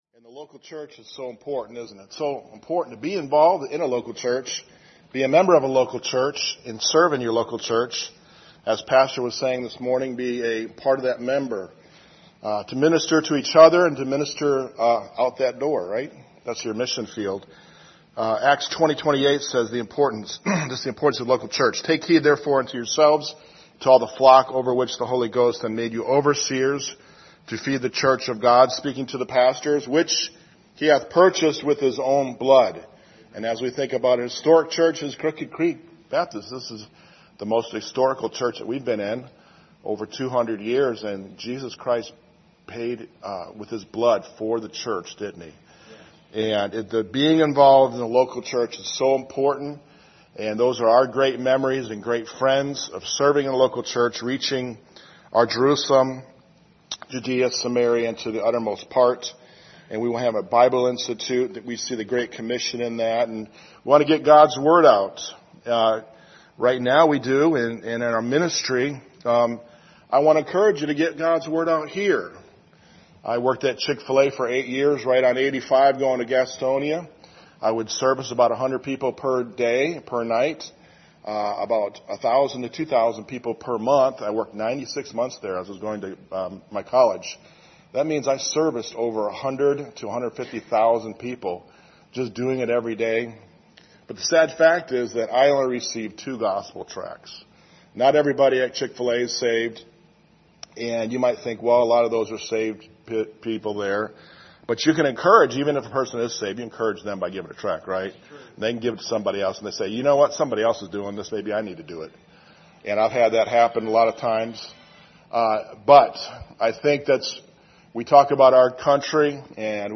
Missionary Presentation
Acts 20:28 Service Type: Sunday Evening Topics